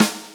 snaredrum1_3.ogg